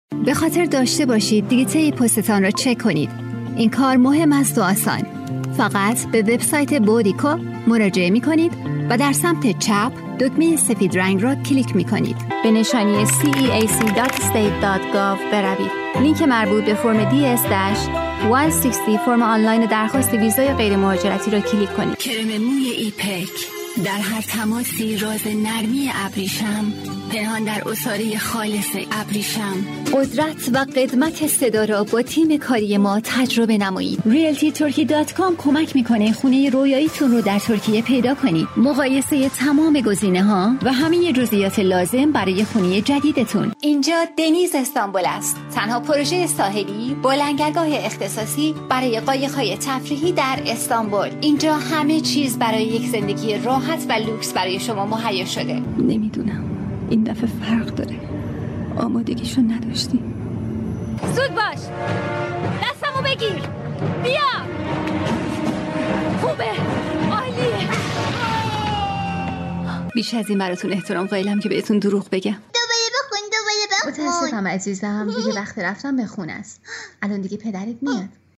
• 4Perisian Female No.2
Brand Ads【Active and Lovely】